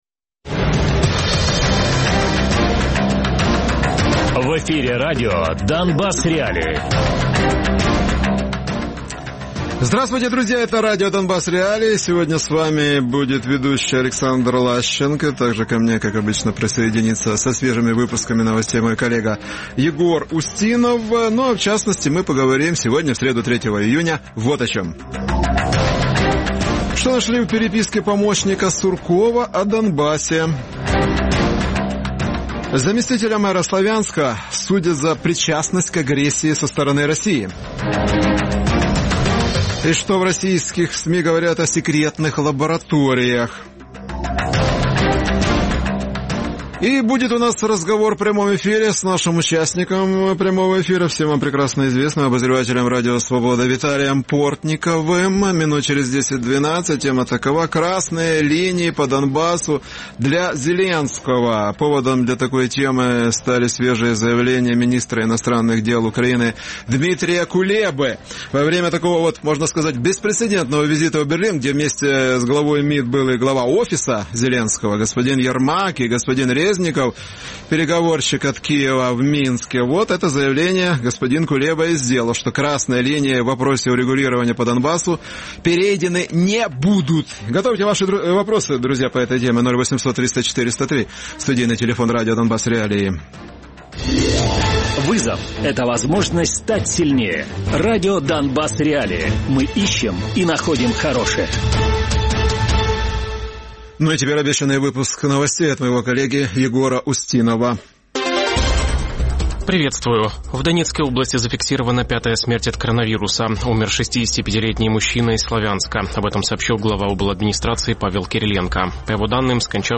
Гість програми: Віталій Портников - журналіст, оглядач Радіо Свобода.